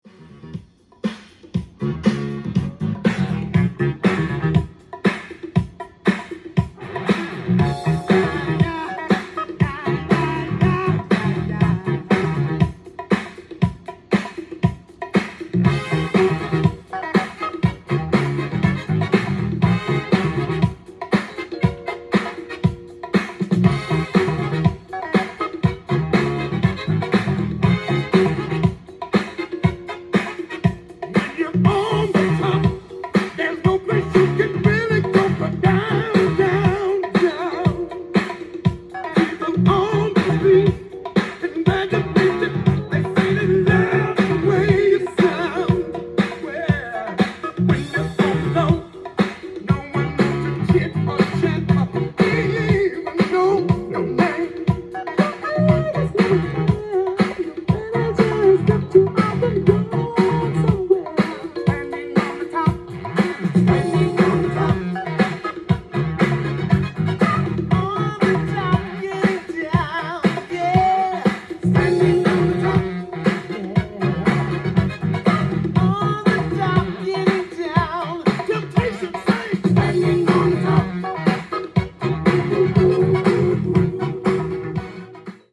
ジャンル：Soul-7inch-全商品250円
店頭で録音した音源の為、多少の外部音や音質の悪さはございますが、サンプルとしてご視聴ください。